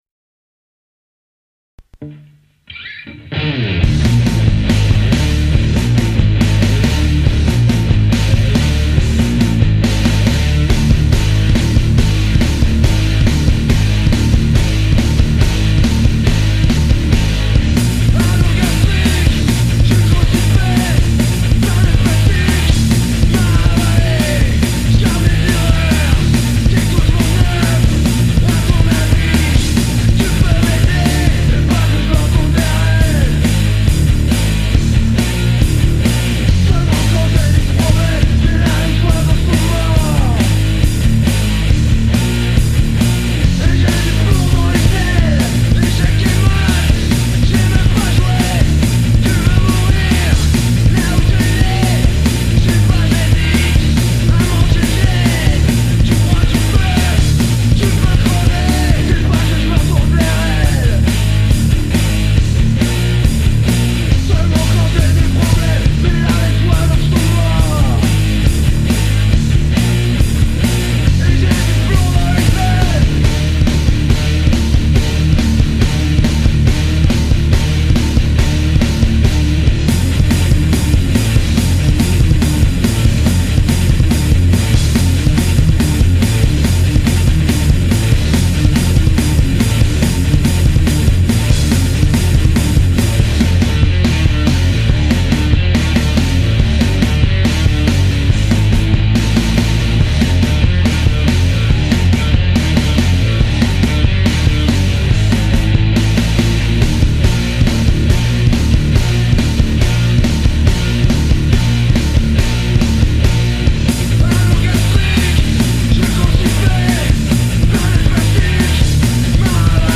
guitare voix boite à ryhtme